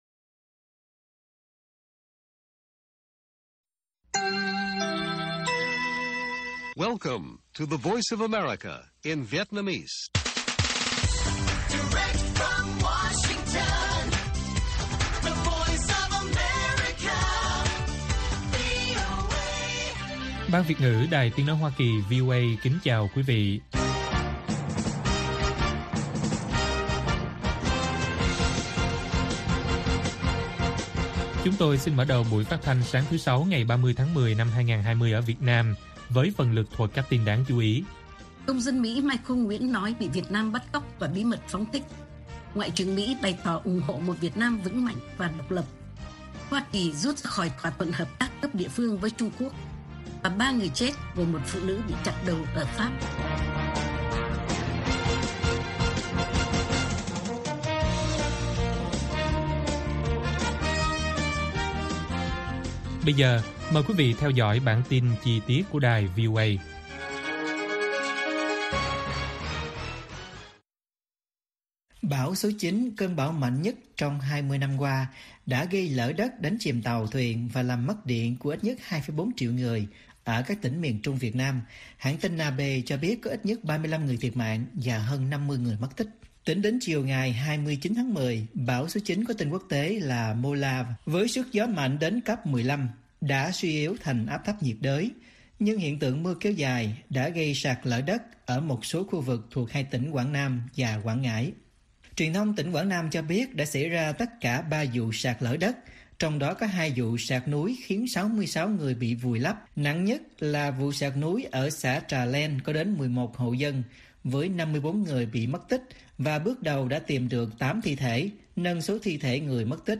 Bản tin VOA ngày 30/10/2020